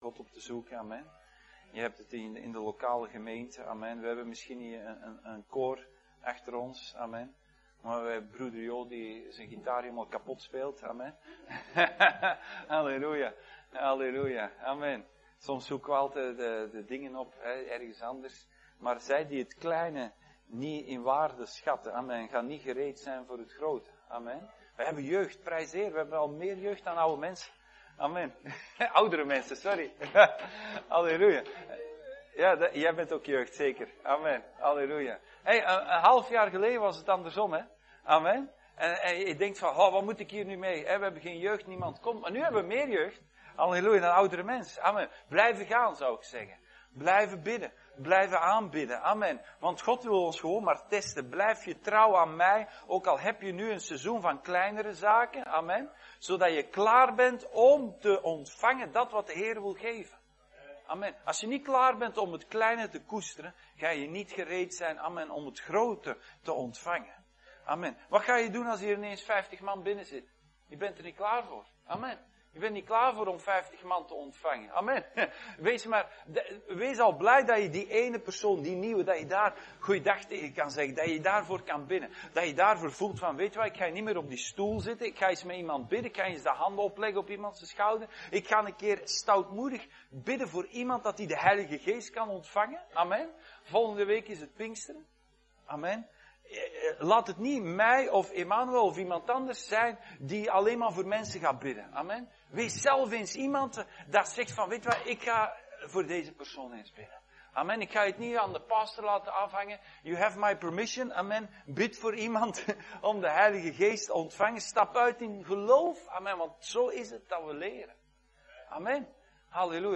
2024 Dienstsoort: Zondag Dienst « Zoals het hart is